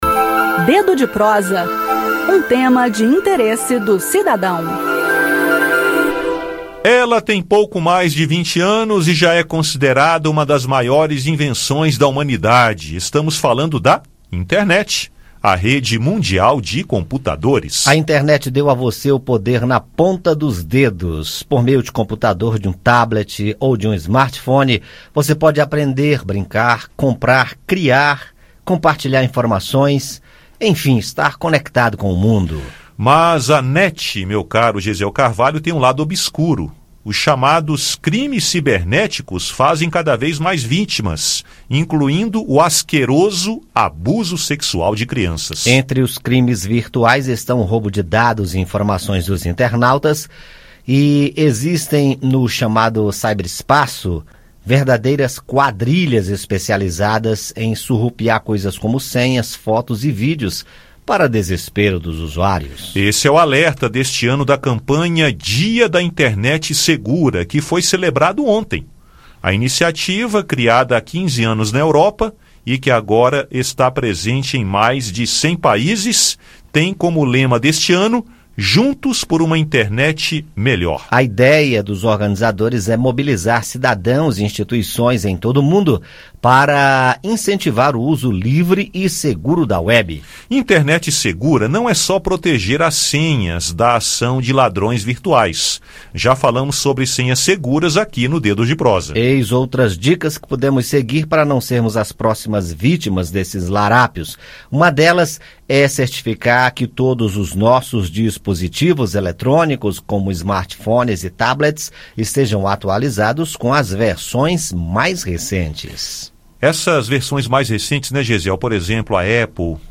O Dedo de Prosa desta quarta-feira (06), é sobre esse assunto. Ouça o áudio com o bate papo.